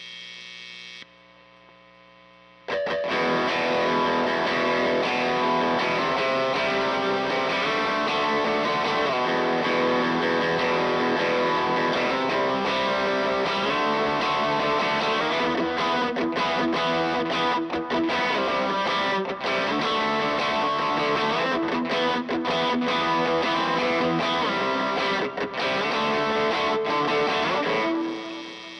Guitar_Y2K_156bpm_Emin
Guitar_Y2K_156bpm_Emin.wav